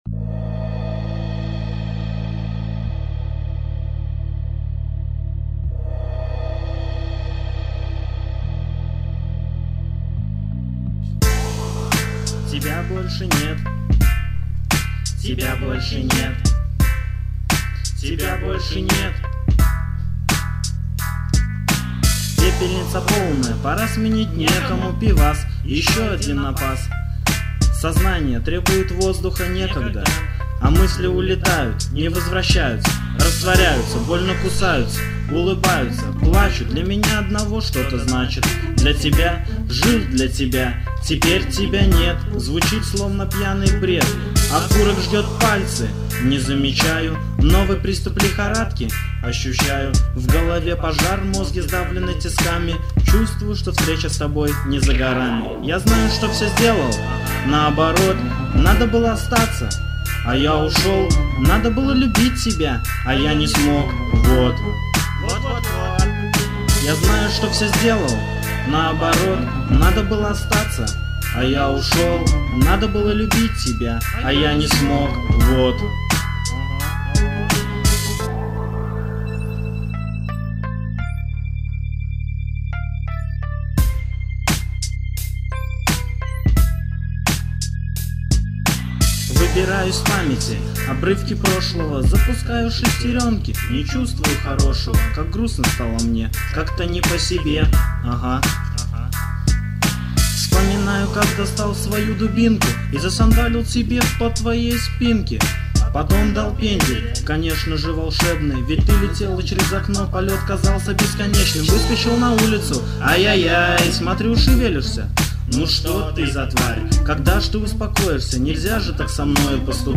Новое:, 2004/2005 Рэп Комментарии